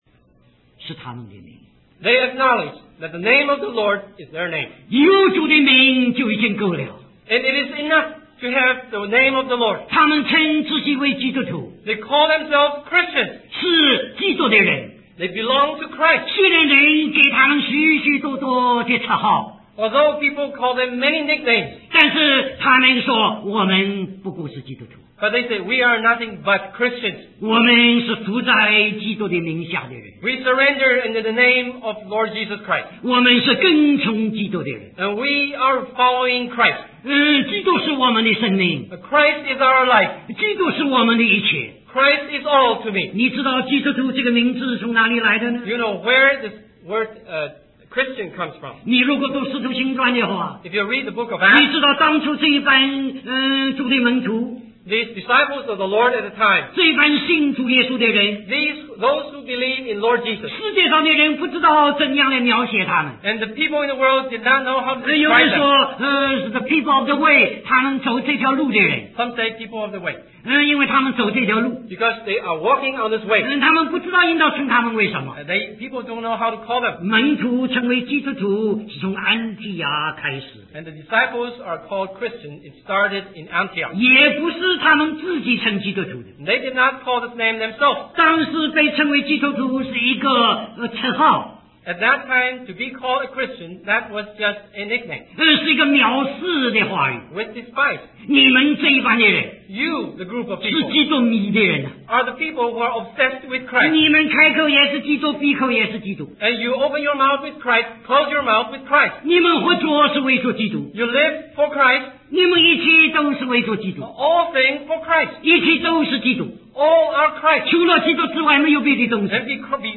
In this sermon, the preacher discusses the story of Balak and Balaam from the book of Numbers.